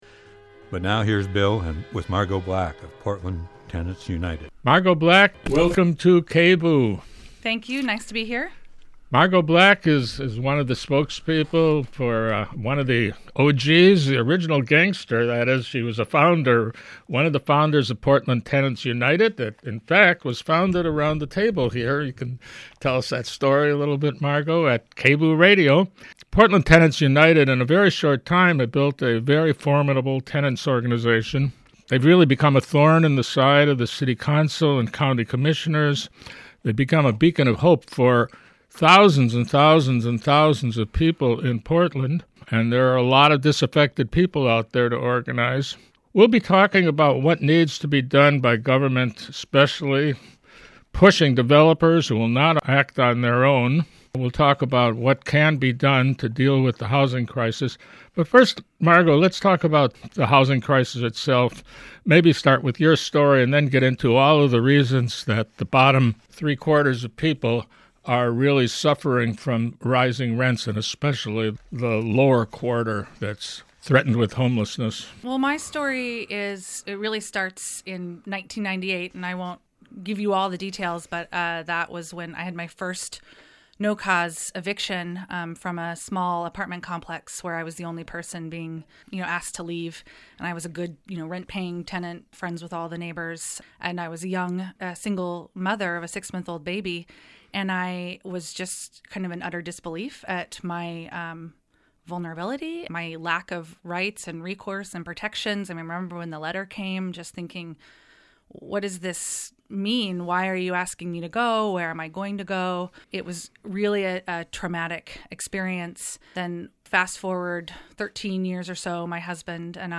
Discussion of the impact of rising rents and evictions on tenants.